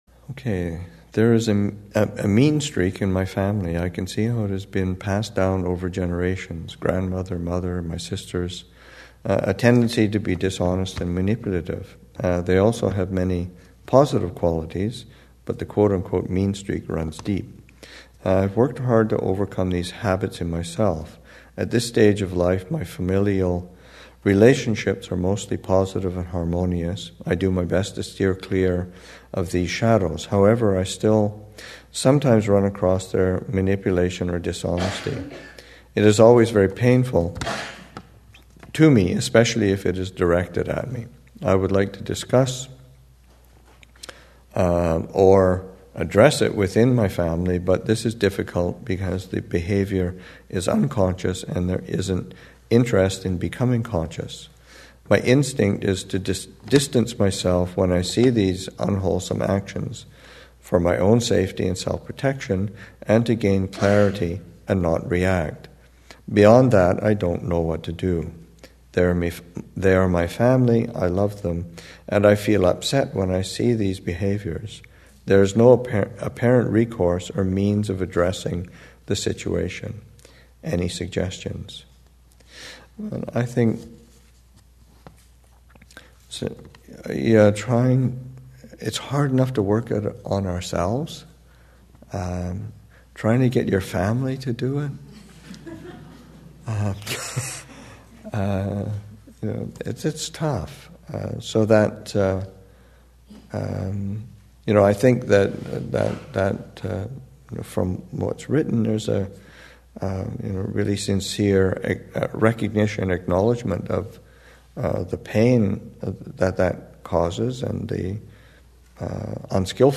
2015 Thanksgiving Monastic Retreat, Session 4 – Nov. 24, 2015